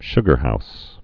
(shgər-hous)